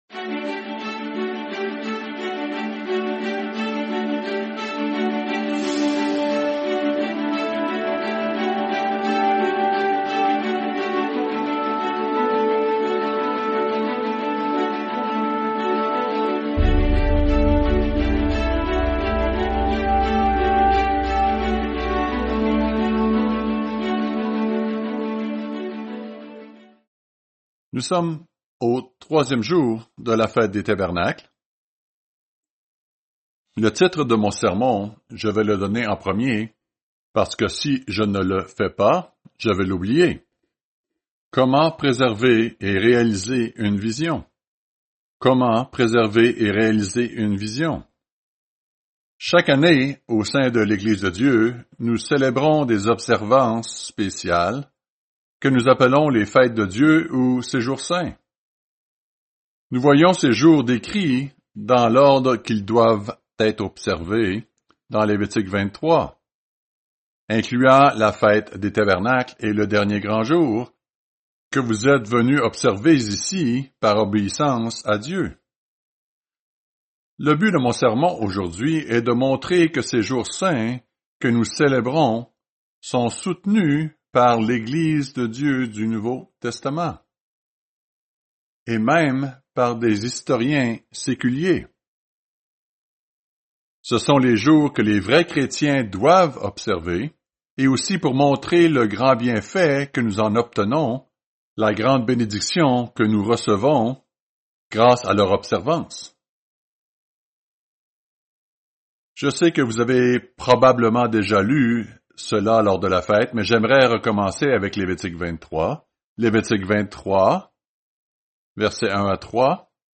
Fête des Tabernacles – 3e jour Comment préserver et réaliser une vision?